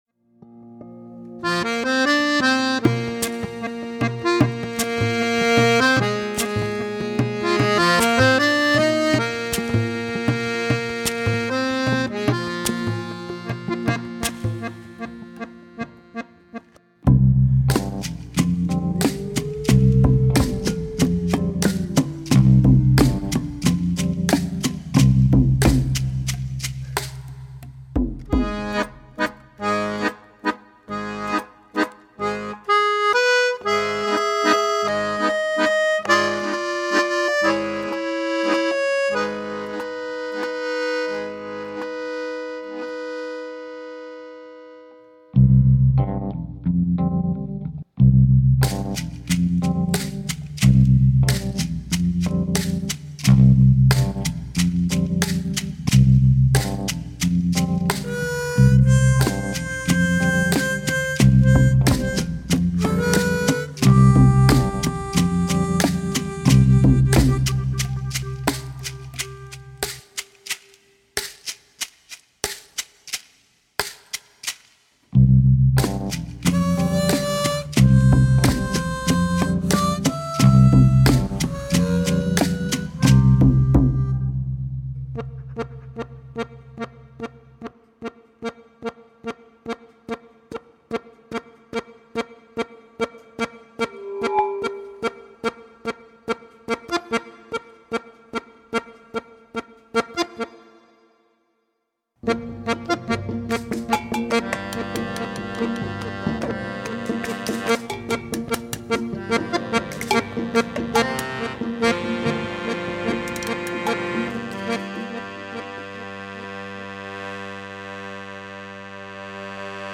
Soundtrack Snippets